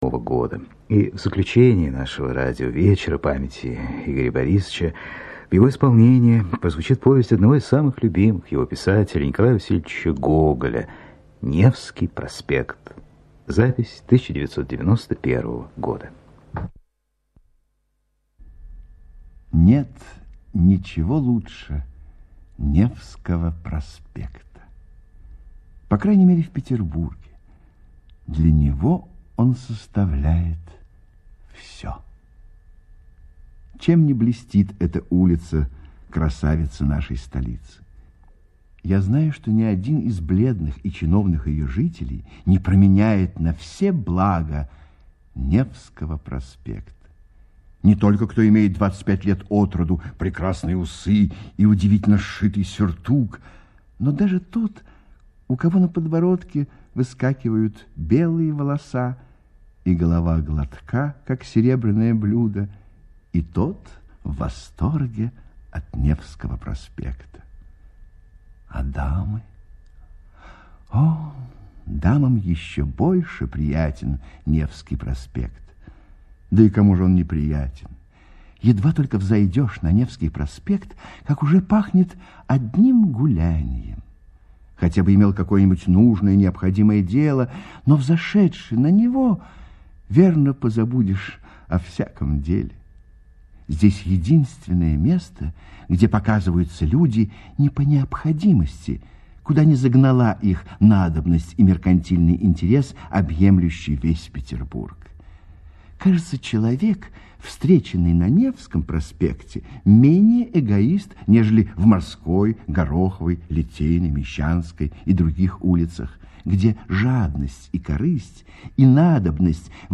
Аудиокнига Невский проспект
Качество озвучивания весьма высокое.